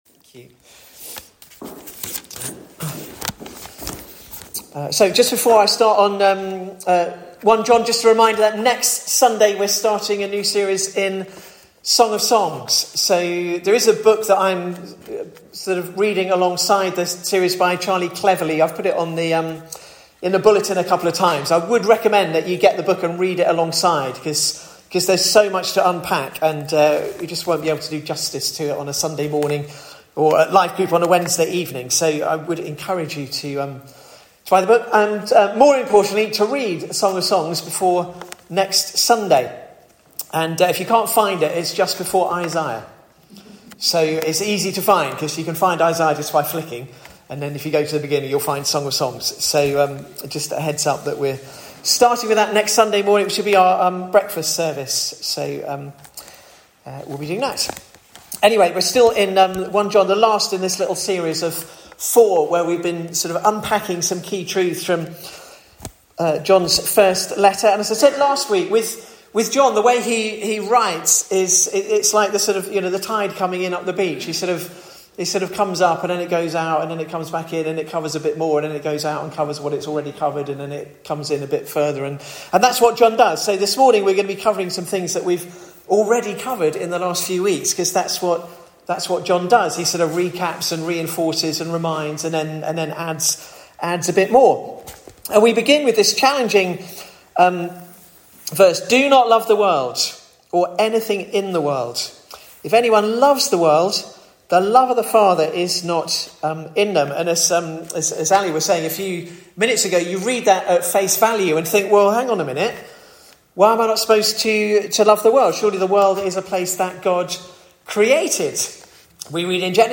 Sermons recorded at Bolney Village Chapel in West Sussex